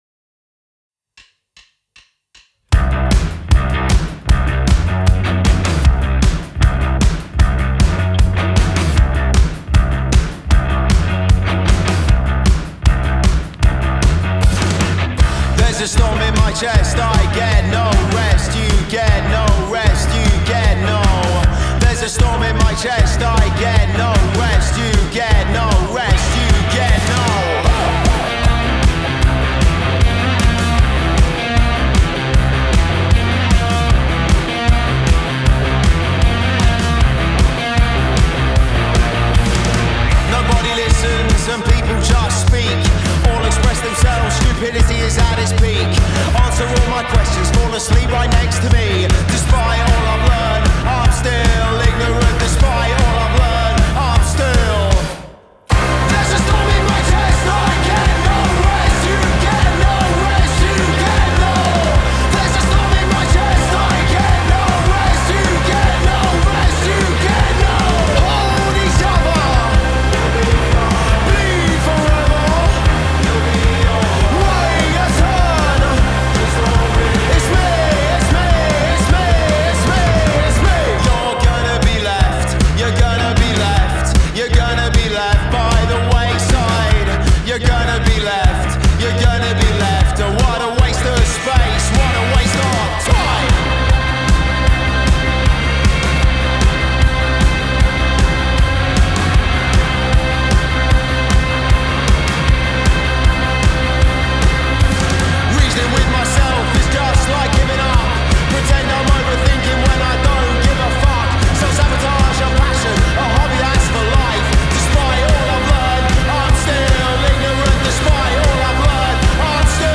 Post-Punk/Noise Rock